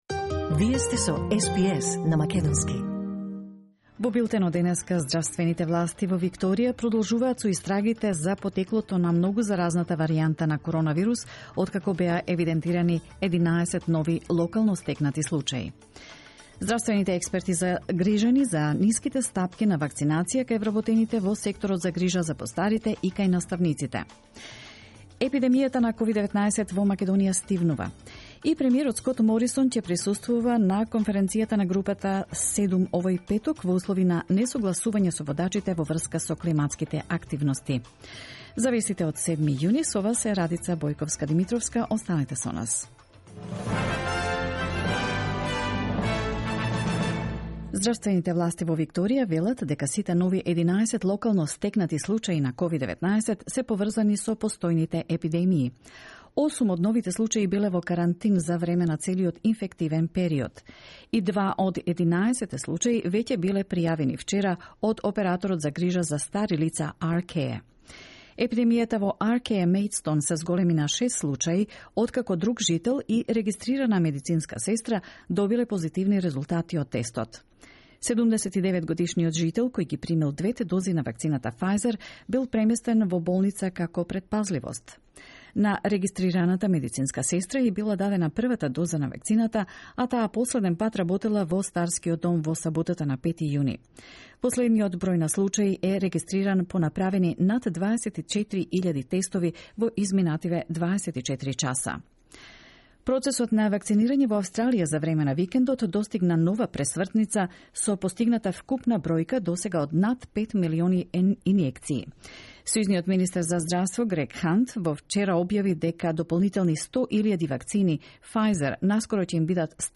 SBS News in Macedonian 7 June 2021